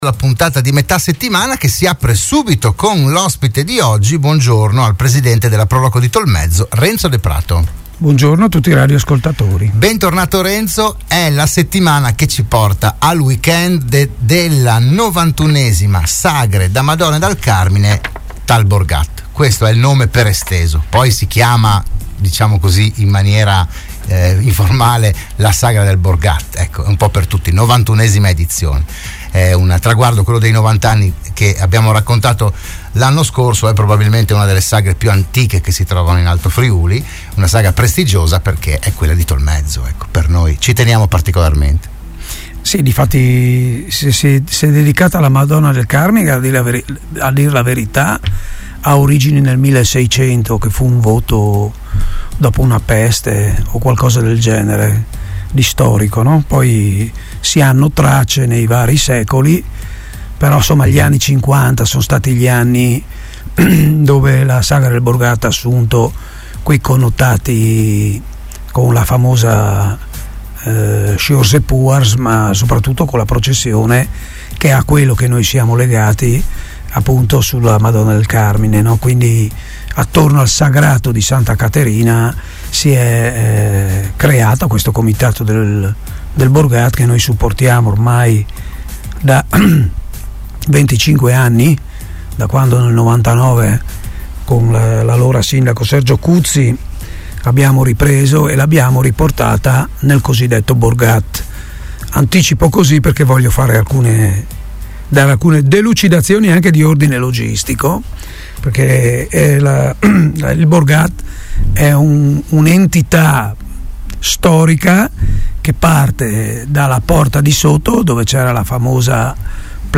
Ne ha parlato a Radio Studio Nord